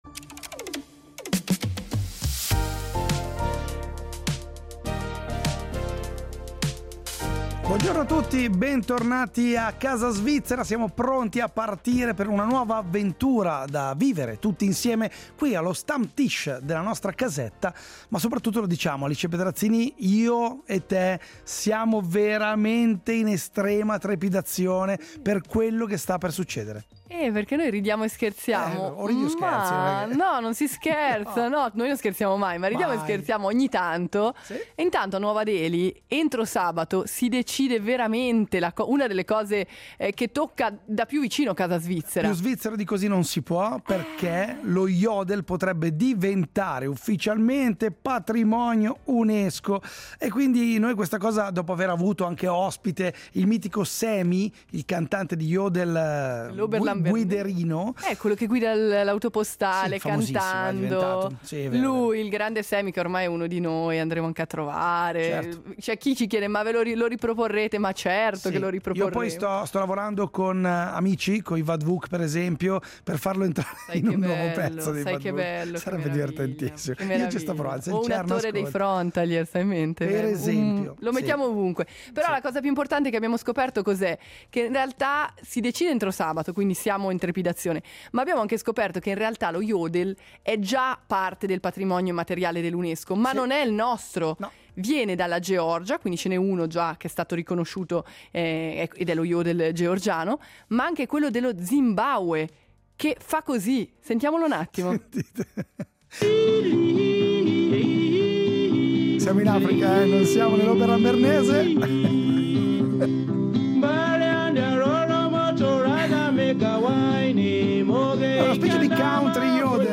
Poi è arrivato il mercoledì dei produttori: in studio